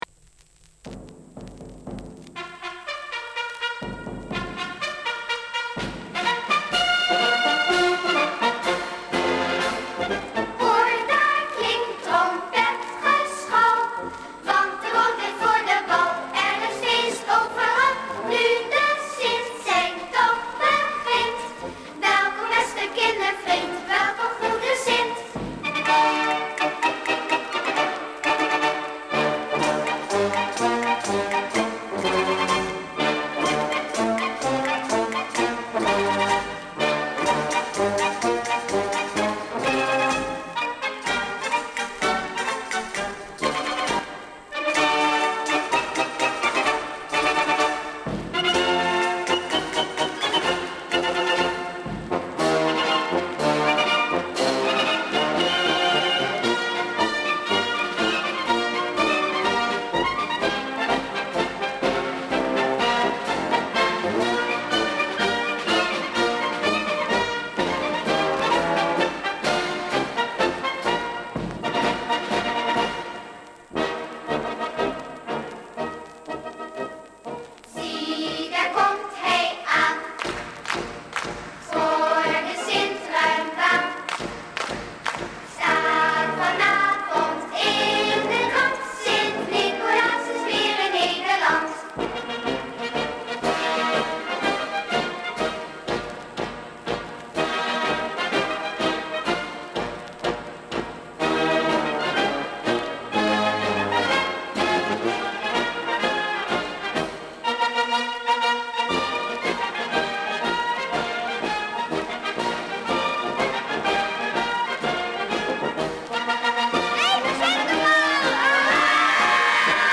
Sinterklaas intochtsmars Marsmuziek met zang